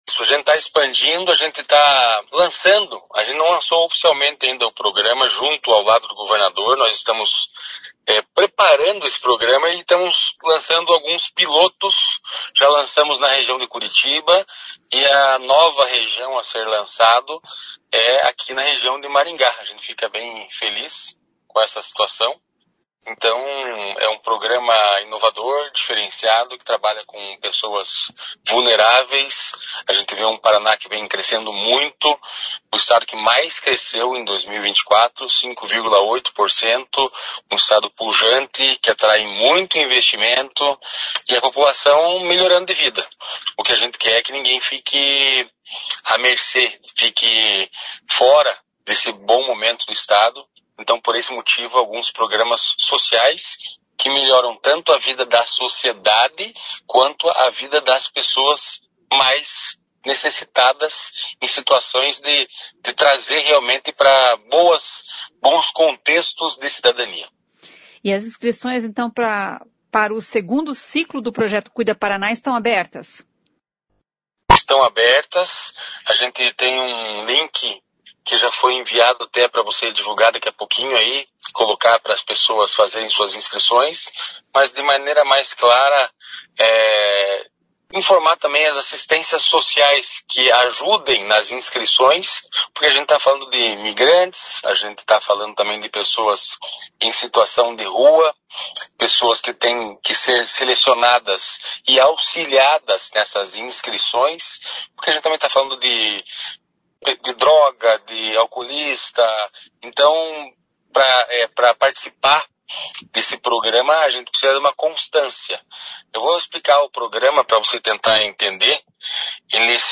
Ouça o que diz o secretário de Justiça e Cidadania do Paraná, Santin Roveda: